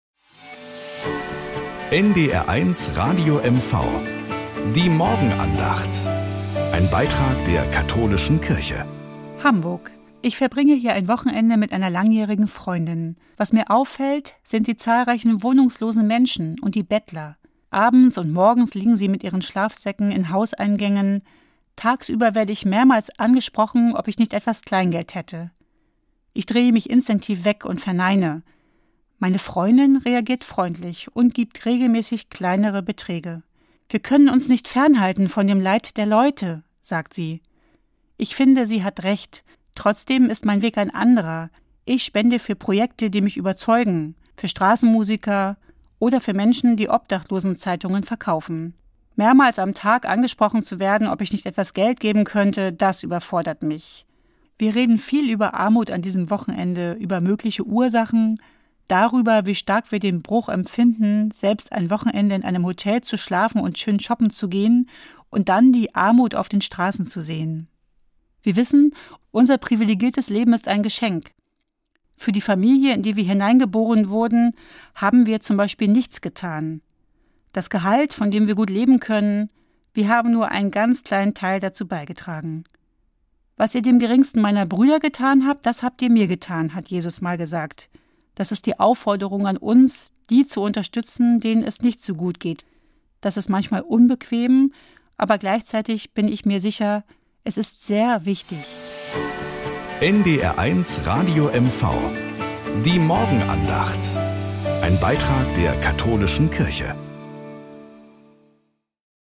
Nachrichten aus Mecklenburg-Vorpommern - 27.04.2025